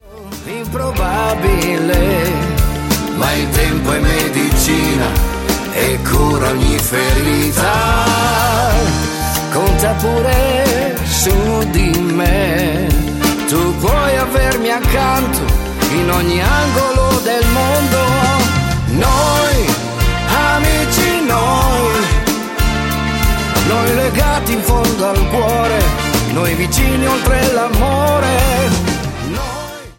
MODERATO POP  (03,40)